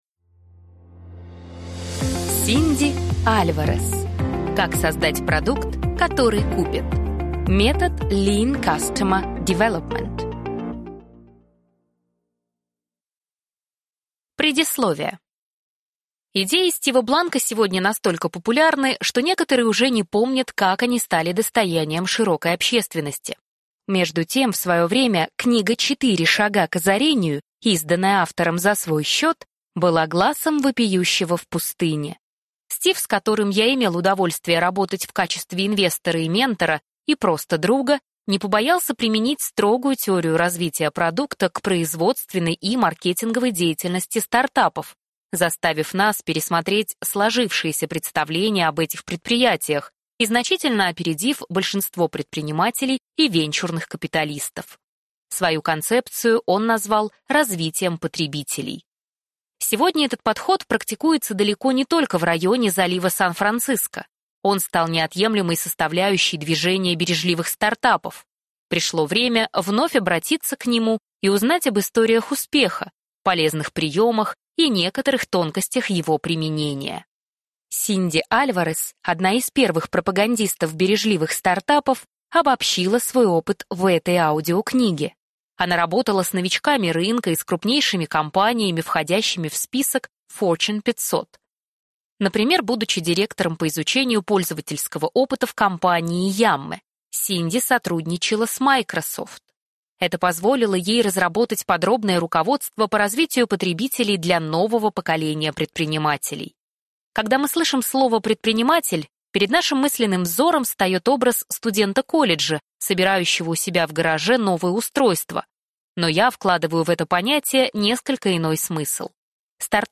Аудиокнига Как создать продукт, который купят. Метод Lean Customer Development | Библиотека аудиокниг